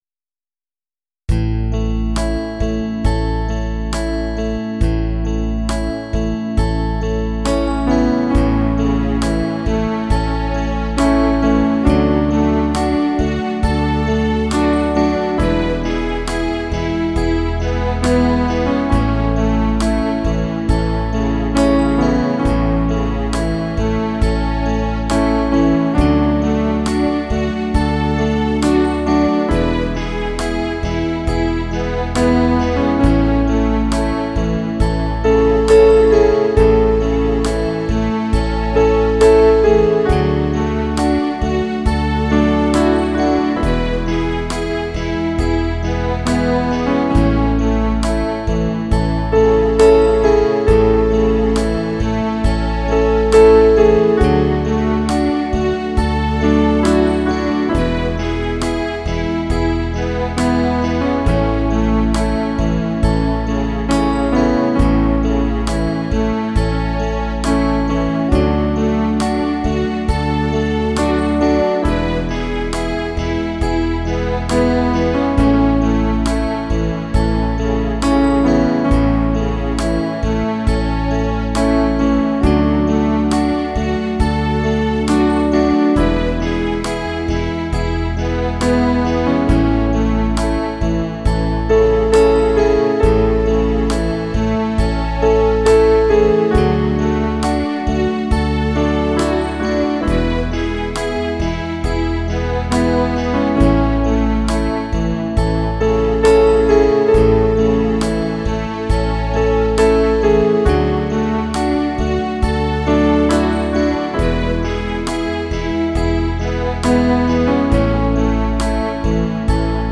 T. + M.: Roland Patzleiner
Instrumentalaufnahme